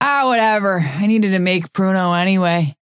Vyper voice line - Ah, whatever. I needed to make pruno anyway.